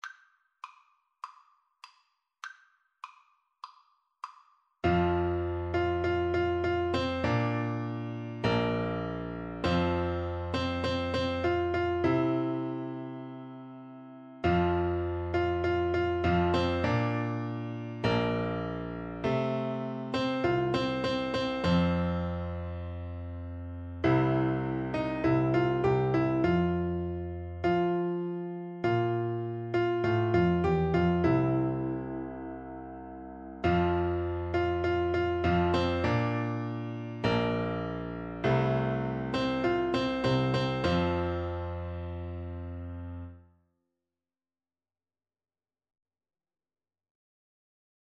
Piano Duet  (View more Easy Piano Duet Music)